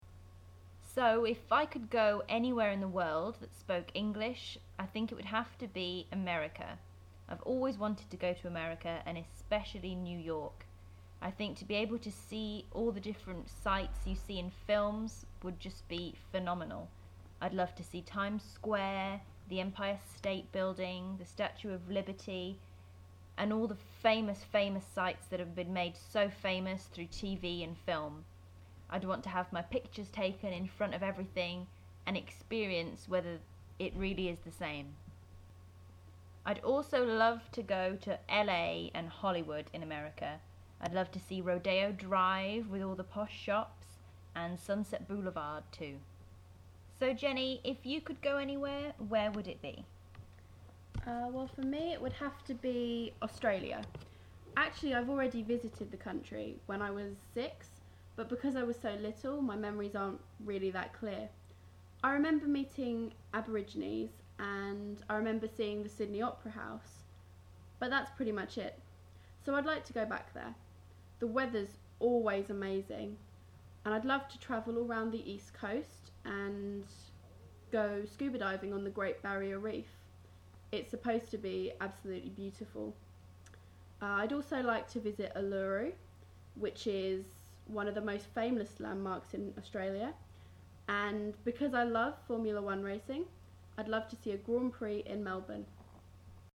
Britannique / Américain